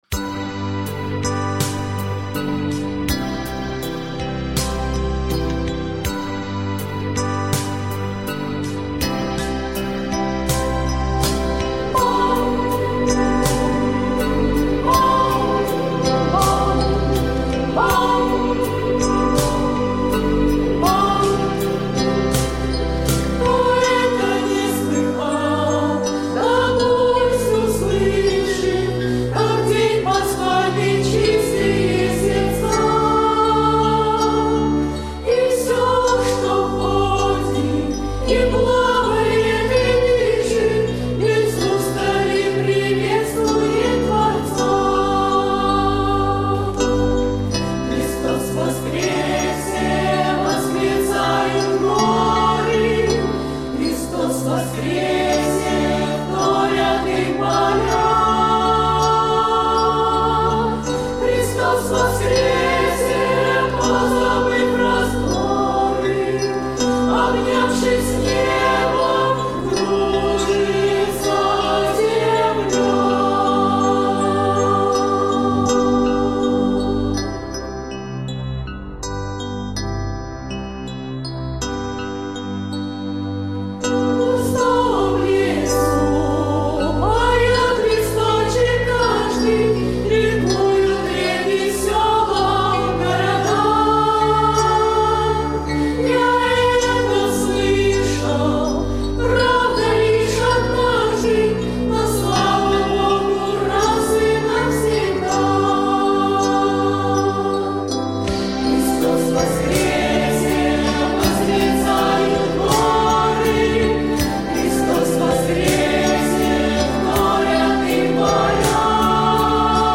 Исполняет любительский хор «Распев». Песня «Пасхальная». Автор — Юлия Березова/ Performed by the amateur choir «Sings».